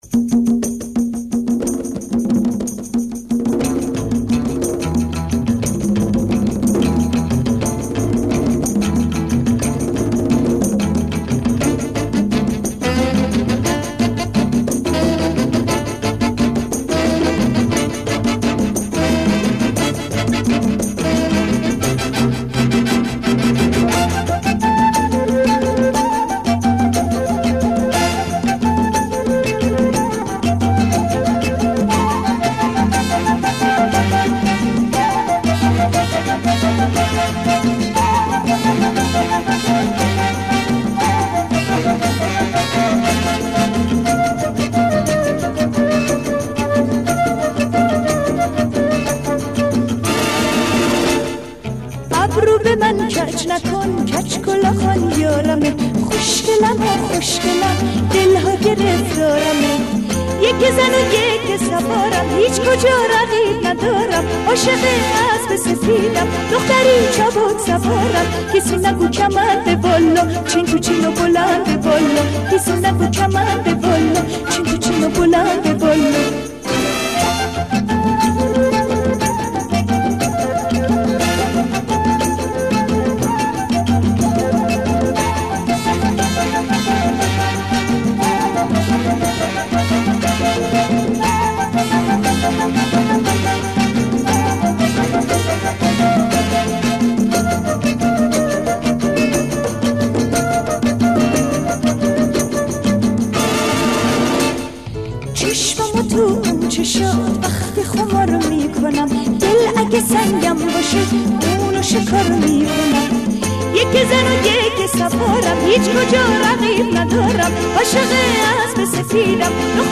اهنگ شاد ایرانی
اهنگ شاد قدیمی